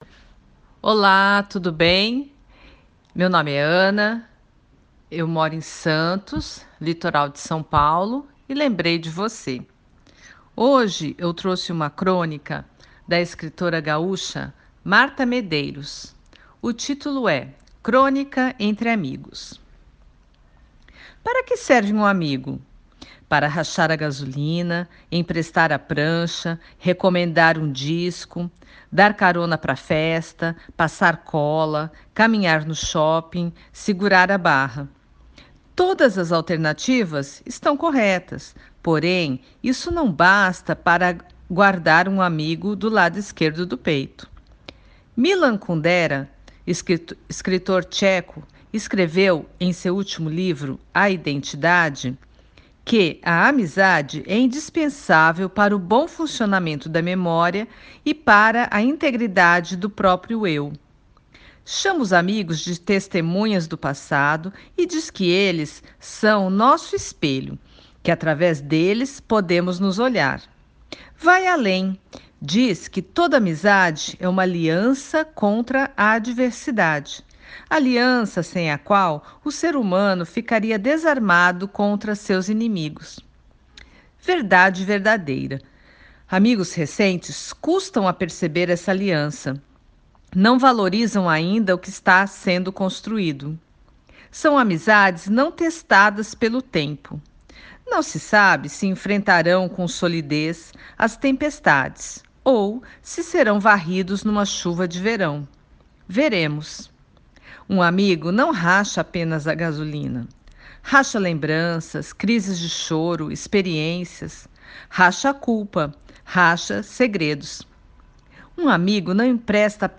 Crônica